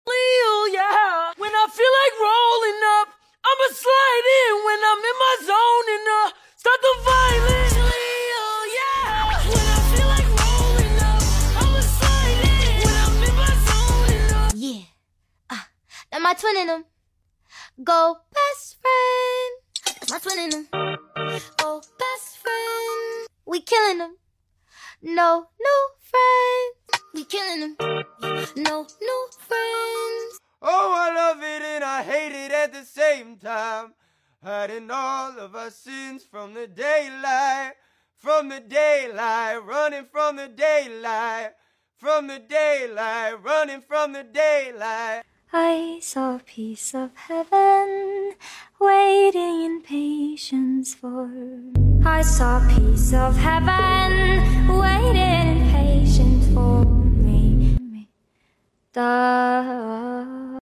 Autotune vs No Autotune ✅